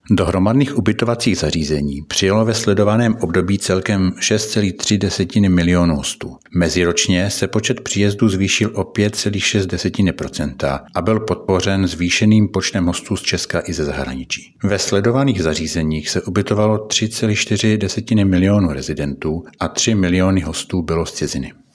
Vyjádření